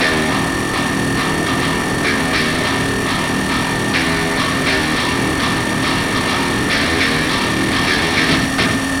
gatling_spin.wav